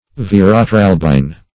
Search Result for " veratralbine" : The Collaborative International Dictionary of English v.0.48: Veratralbine \Ver`a*tral"bine\, n. (Chem.)